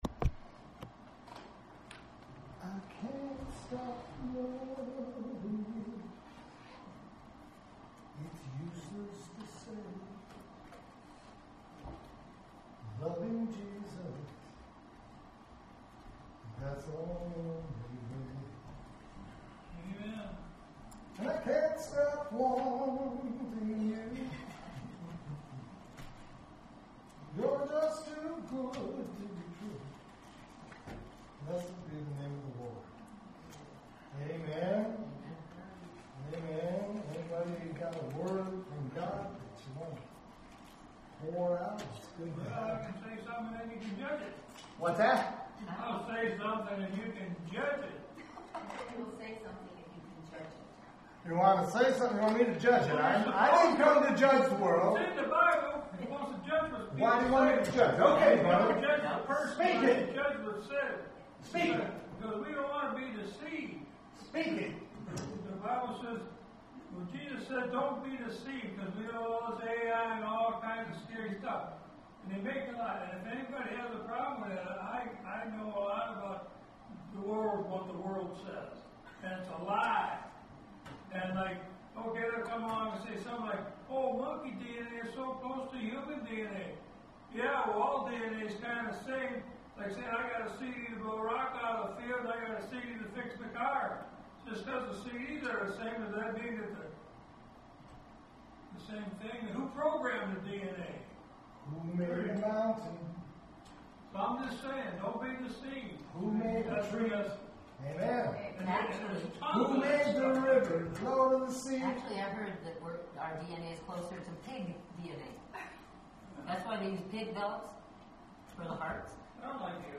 Here you’ll find a selection of audio recordings from Hosanna Restoration Church.